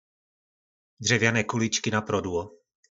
Slovník nářečí Po našimu